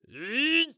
ZS蓄力1.wav
ZS蓄力1.wav 0:00.00 0:00.79 ZS蓄力1.wav WAV · 68 KB · 單聲道 (1ch) 下载文件 本站所有音效均采用 CC0 授权 ，可免费用于商业与个人项目，无需署名。
人声采集素材/男3战士型/ZS蓄力1.wav